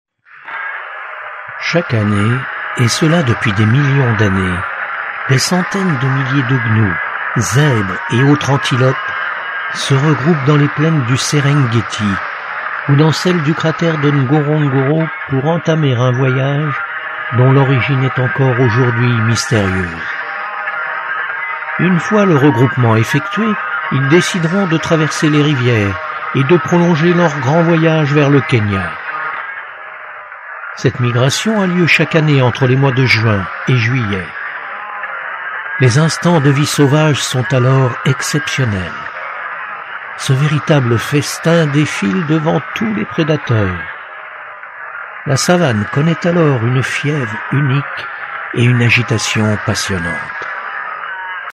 Comédien depuis vingt ans, ma voix au timbre médium sait être selon les exigences,tour à tour,posée,sérieuse,claire, pédagogique,explicative, rassurante mais aussi confidentielle, mystérieuse ou encore joueuse,exubérante, émerveillée...
Sprechprobe: Sonstiges (Muttersprache):